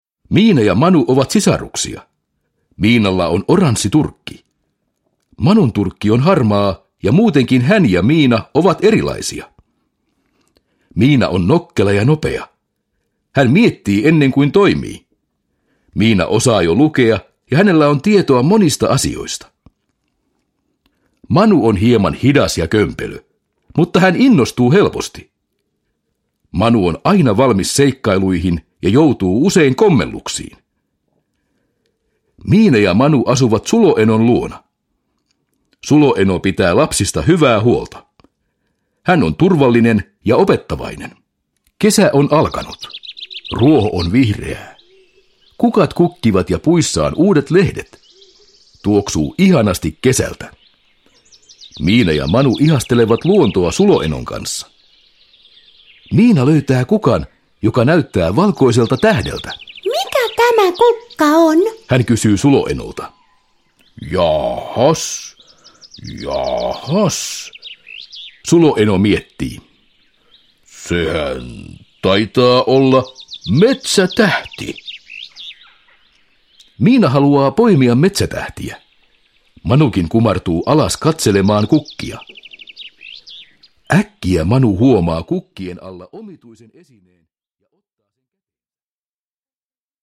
Miina ja Manu Kiinassa – Ljudbok – Laddas nerTillbaka till BilderböckerMiina ja Manu koulutiellä – Ljudbok – Laddas ner
Produkttyp: Digitala böcker